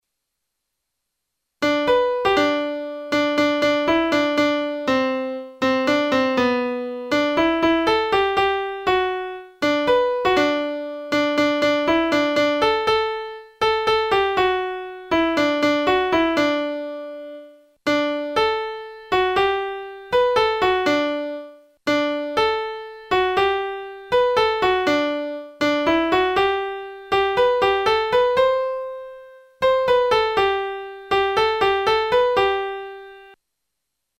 Melodie (Klavier, *.mp3)